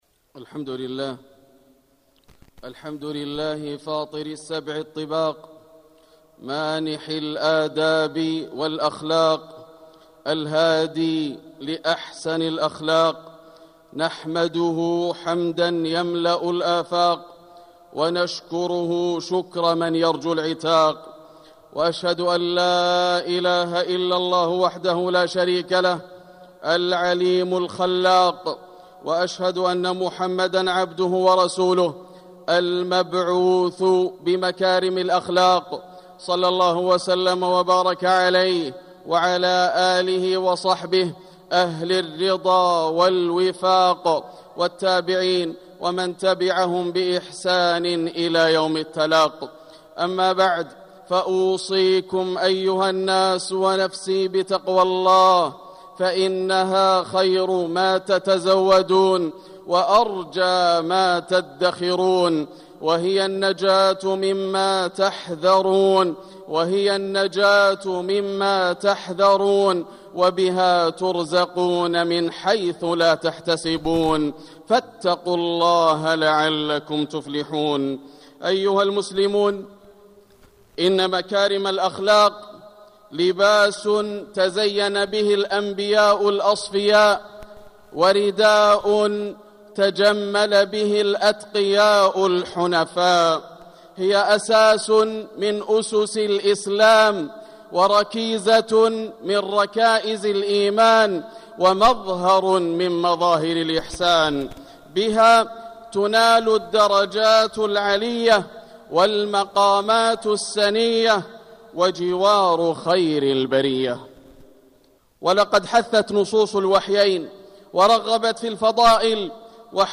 مكة: محاسن الأخلاق - ياسر بن راشد الدوسري (صوت - جودة عالية. التصنيف: خطب الجمعة